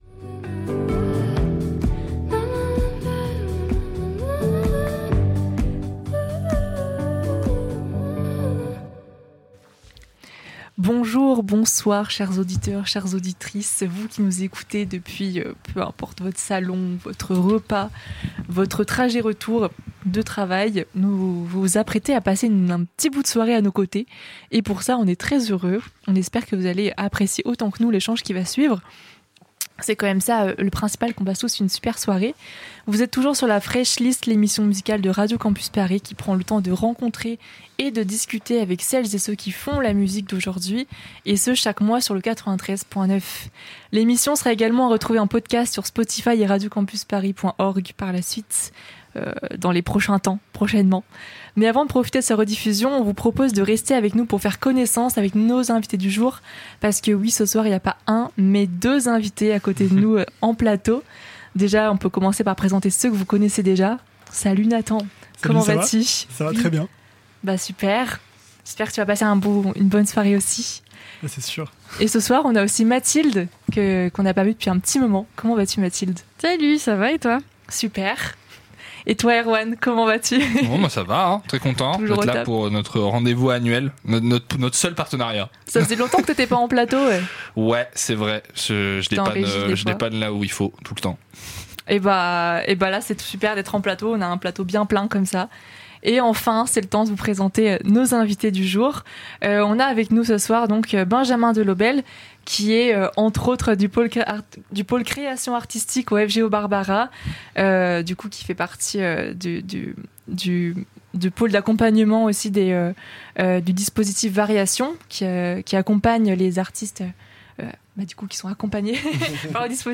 La Fraîche Liste est de retour pour une nouvelle saison en direct ! Un mercredi par mois, l'équipe de programmation vous invite à découvrir un.e artiste de sa sélection à travers un échange sur tout ce qui fait la musique, la création, la composition, et les émotions qui la guide.